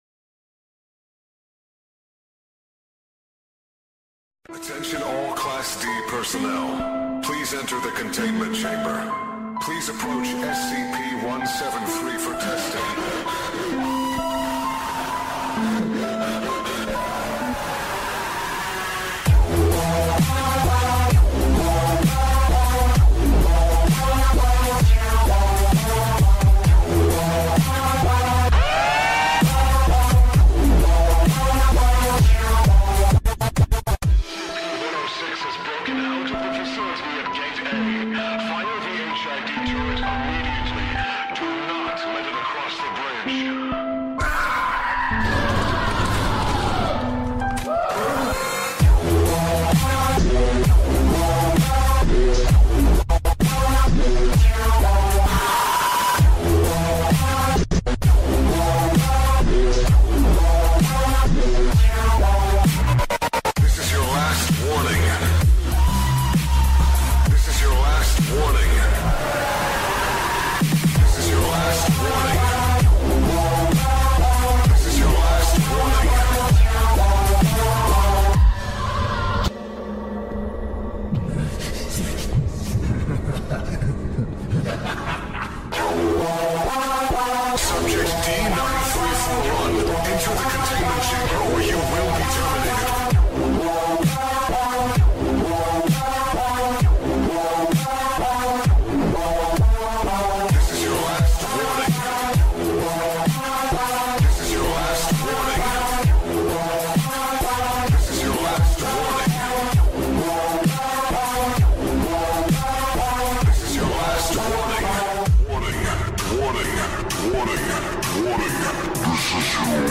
3D sound effects free download